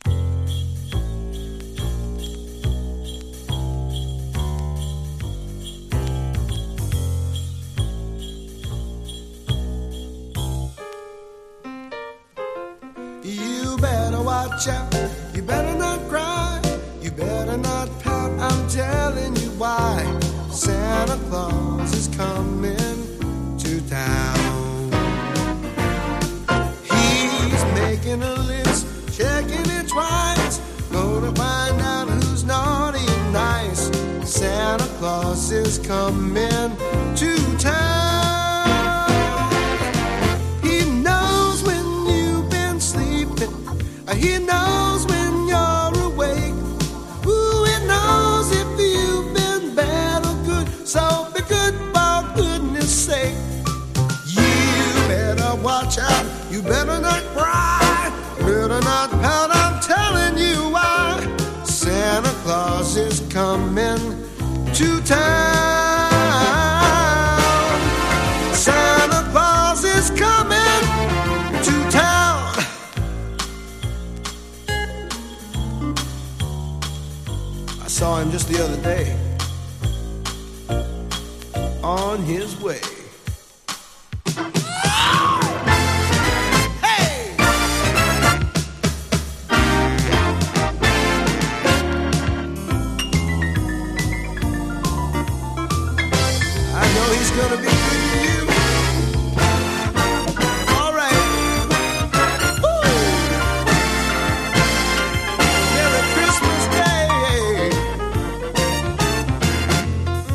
カヴァー
鈴の音とベース・リフで幕開ける